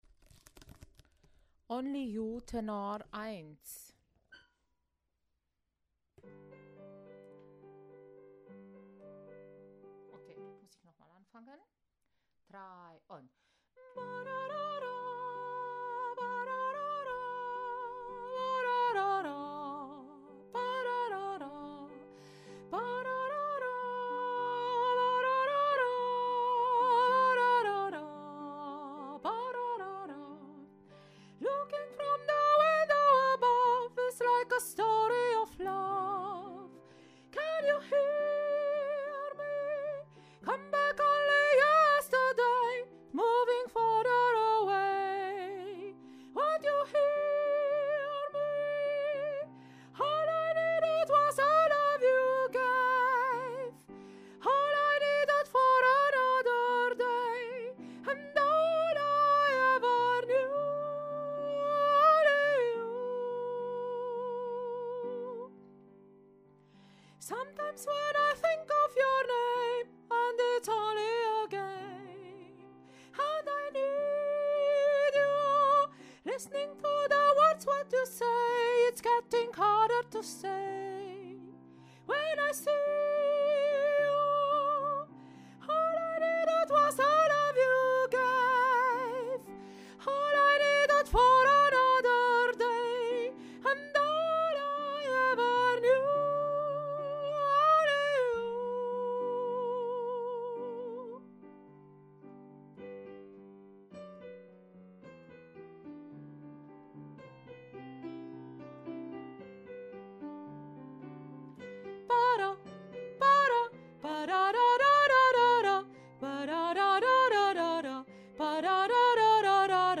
Tenor 1